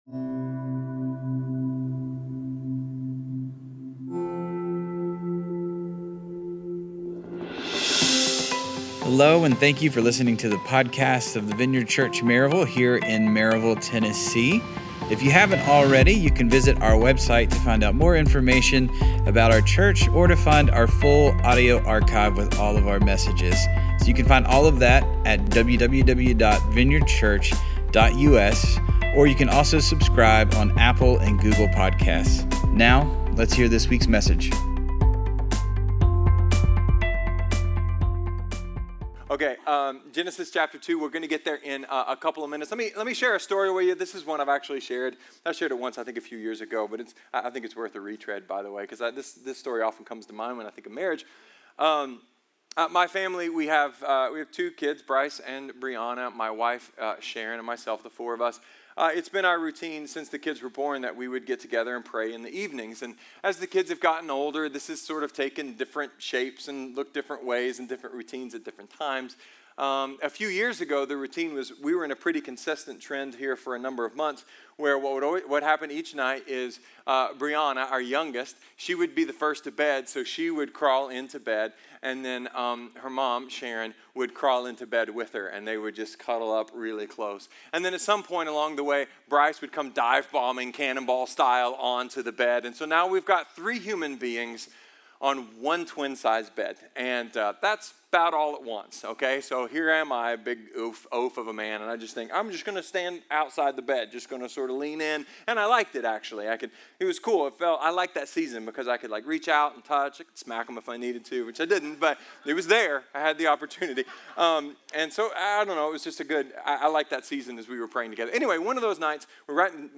A message from the series "Marriage Eternal."